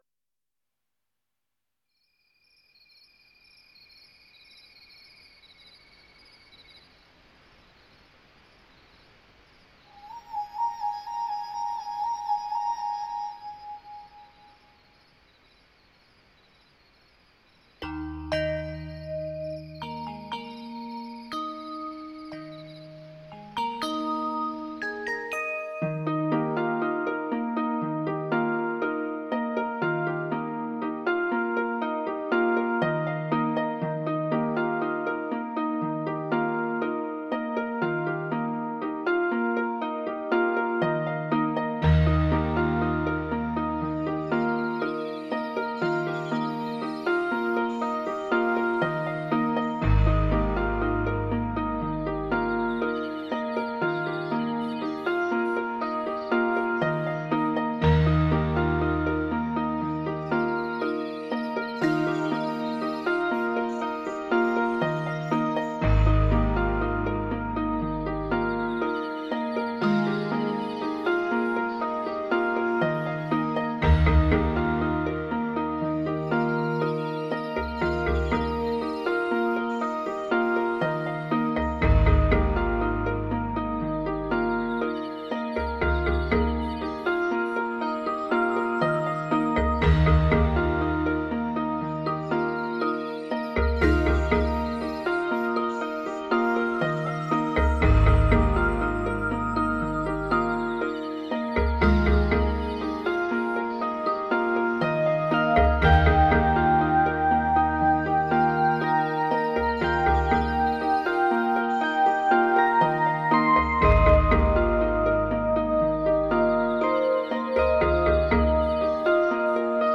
来自荷兰的音乐大师，浪漫是其演奏元素，串串音符如鲜红郁金香上露珠的晶莹剔透；田园诗般的感受就是一种原汁原味的荷兰音乐。